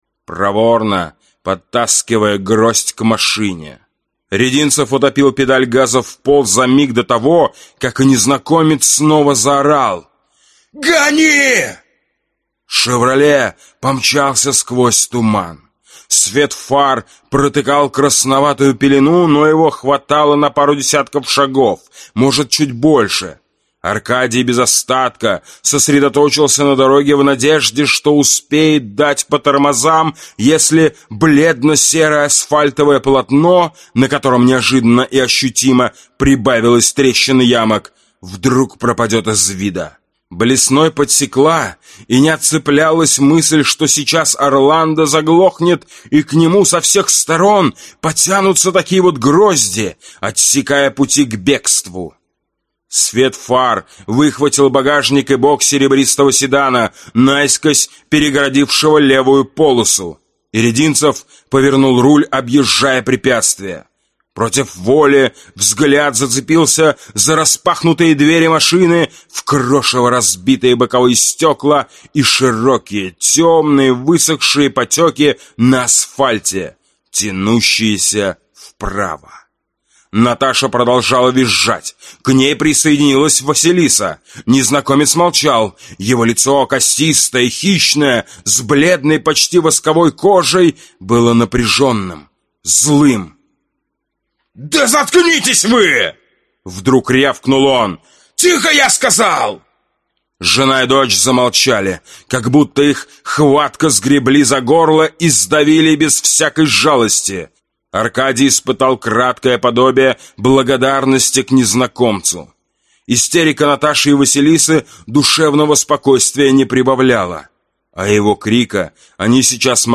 Аудиокнига Голод и ведьма | Библиотека аудиокниг